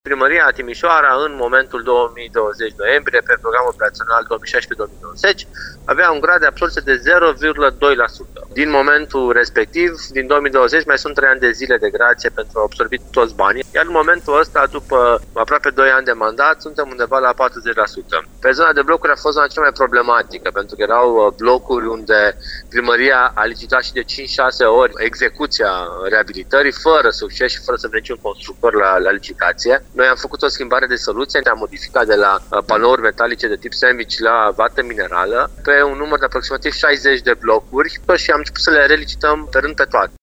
Viceprimarul Ruben Lațcău spune că întârzierea este cauzată de modul în care a acționat fosta administrație, care a insistat pe o soluție de reabilitare pentru care niciun constructor nu s-a prezentat la licitații în mandatul trecut.